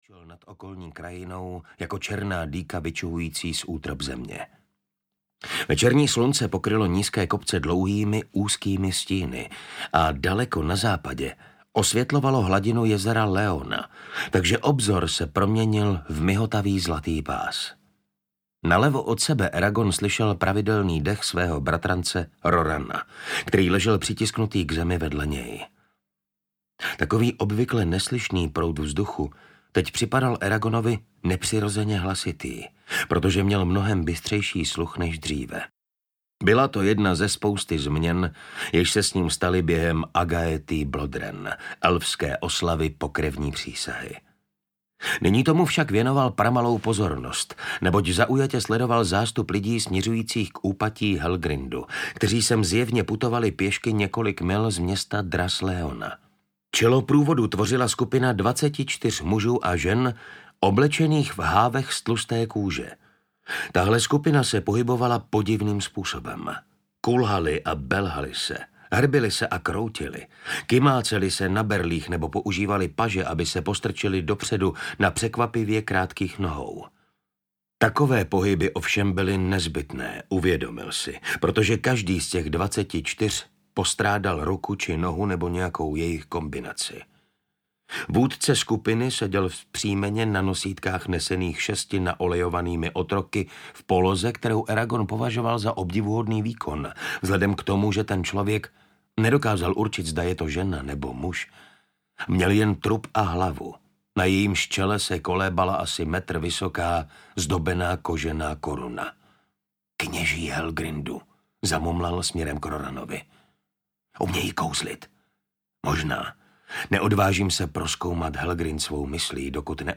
Brisingr audiokniha
Ukázka z knihy
Eragona se Safirou proto čekají další zkoušky v boji, ale v příběhu už nejde jenom o ně…Nechte se i vy pohltit napínavým příběhem o dracích, čarodějích a odvážných hrdinech, zaposlouchejte se do charismatického hlasu Martina Stránského, díky němuž před vámi všechny fantastické postavy doslova ožijí.
• InterpretMartin Stránský